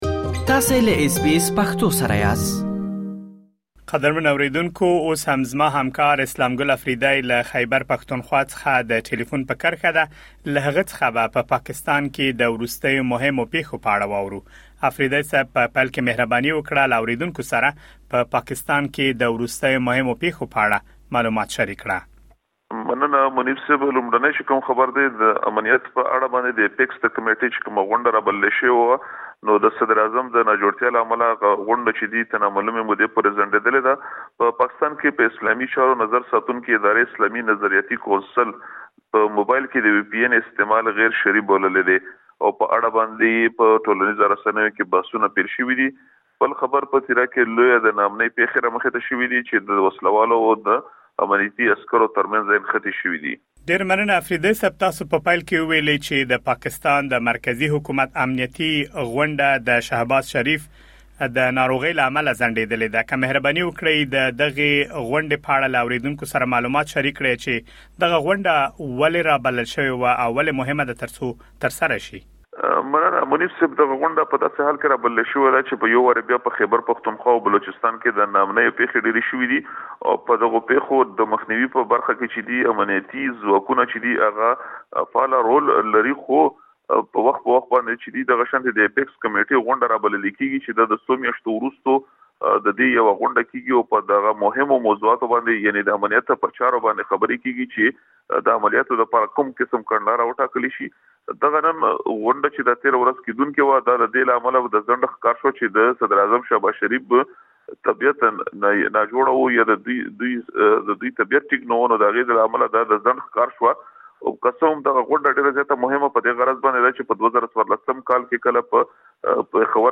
په مرکې کې د لاندې خبرونو په اړه معلومات اورېدلی شئ: د وي پي ان (VPN) په اړه د پاکستان د اسلامي نظرياتي شورا پرېکړه له انتقادونو سره مخ شوې ده.